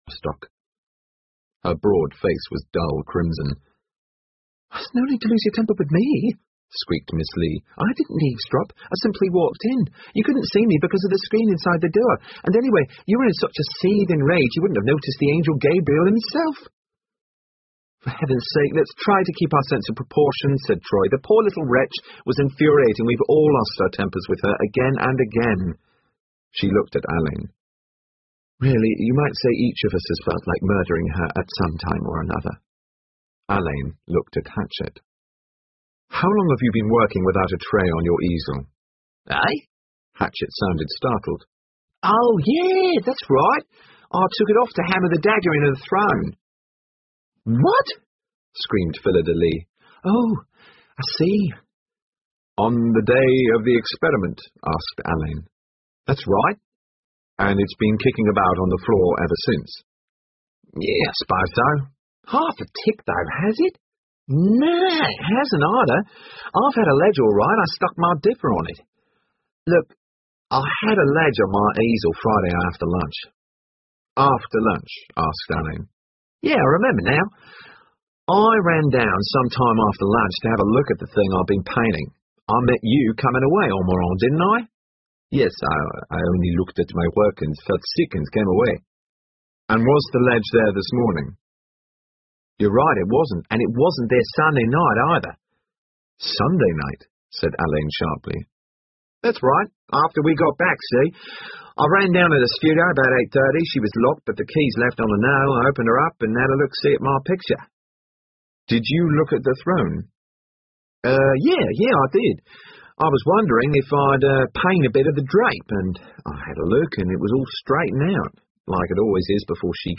英文广播剧在线听 Artists in Crime 13 听力文件下载—在线英语听力室